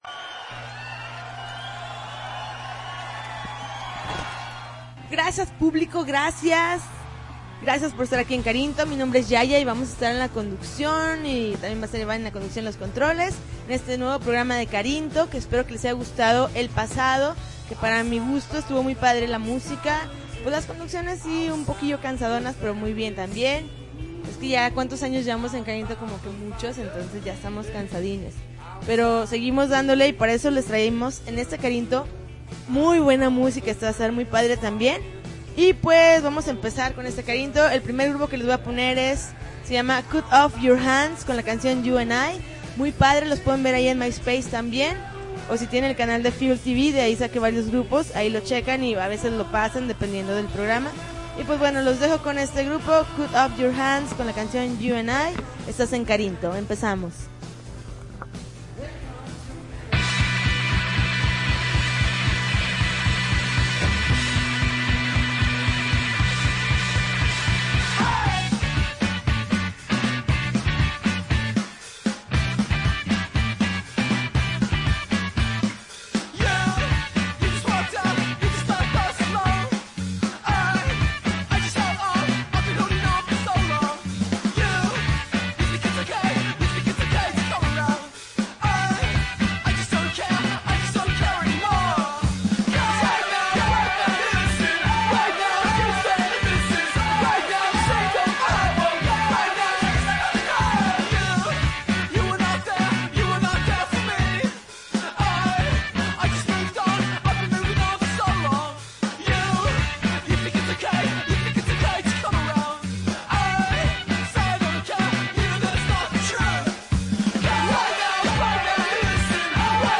November 15, 2009Podcast, Punk Rock Alternativo